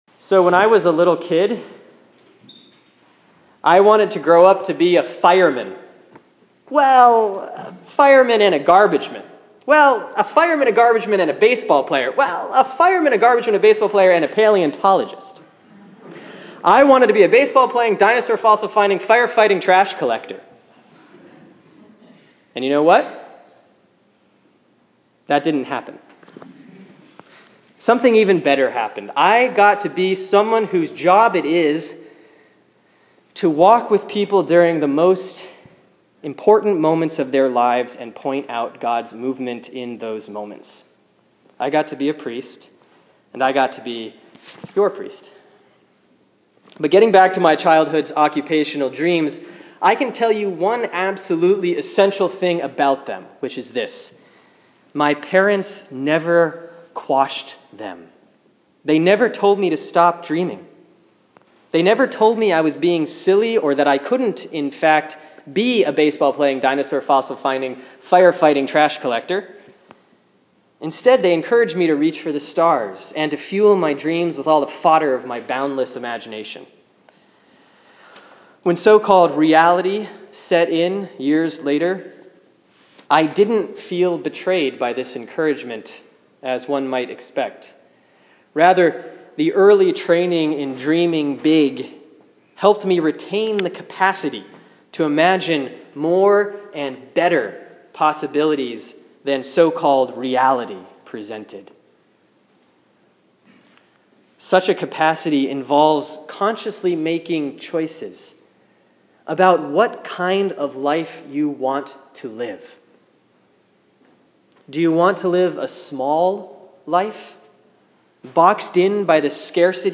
Sermon for Sunday, February, 16, 2014 || Epiphany 6A || Deuteronomy 30:15-20; Matthew 5:21-37